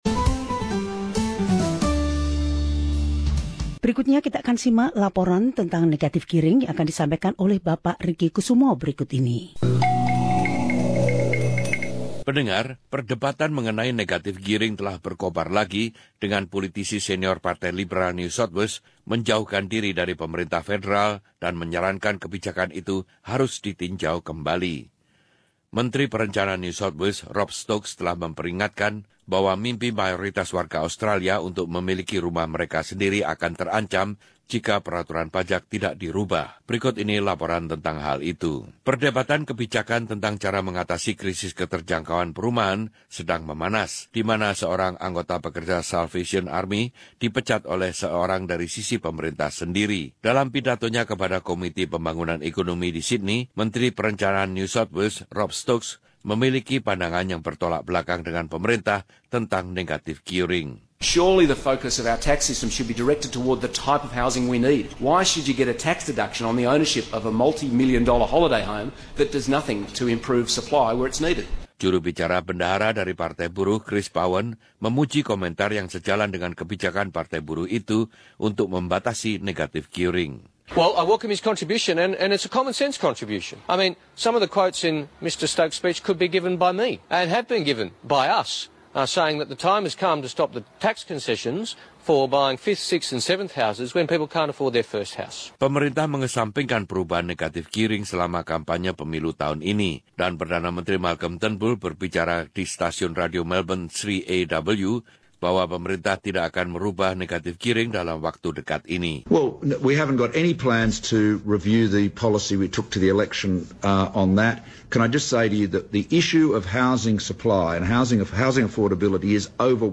Laporan ini meneliti debat tersebut.